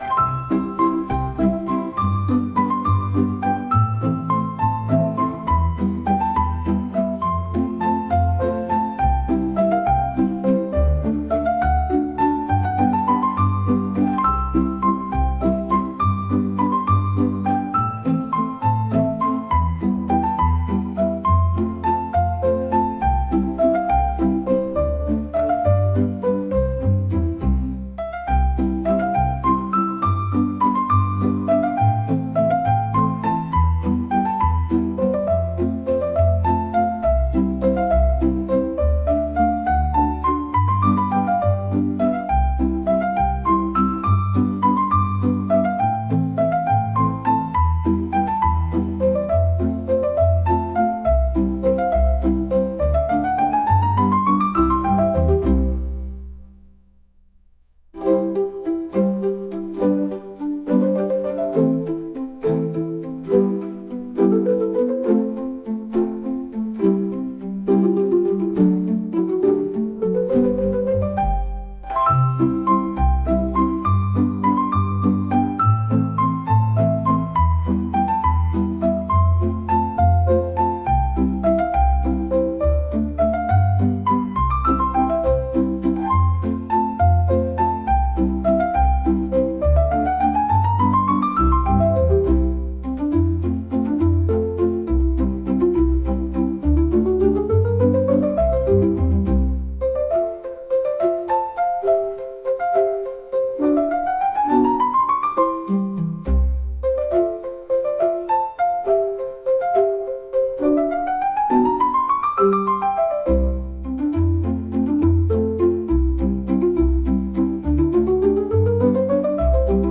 コミカル